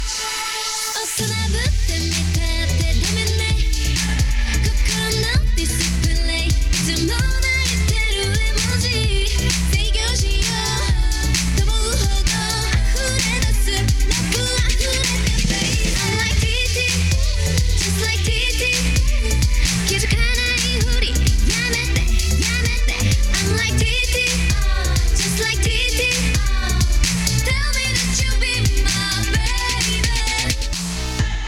・リニアPCMステレオレコーダー：OLYMPUS LS-20M
録音モードは「PCM 96khz/24bit」のハイレゾで、ファイル形式は.WAVでアップしました。
透明感のあるボーカルと重低音が特徴の音数が少ない曲なので、比較視聴に適した曲だと思います。
以下はイヤフォンをレコーダーのステレオマイクに押し付けて録音しました。